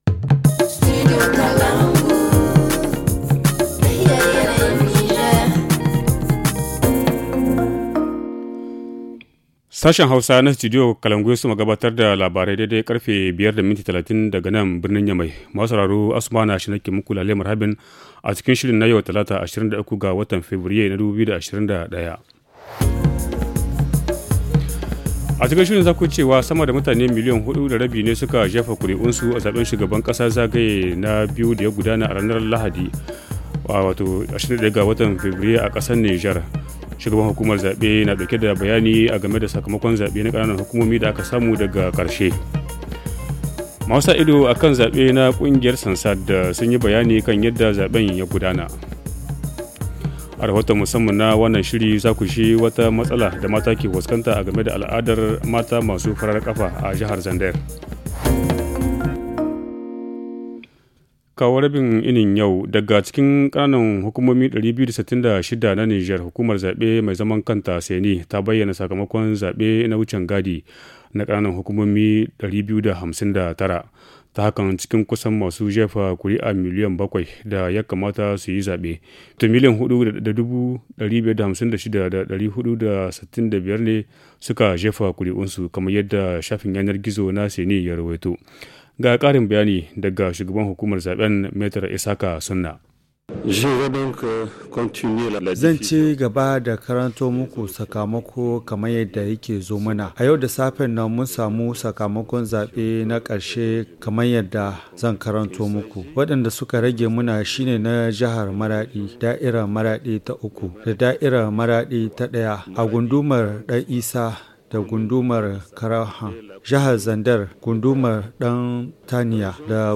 Le journal du 23 février 2021 - Studio Kalangou - Au rythme du Niger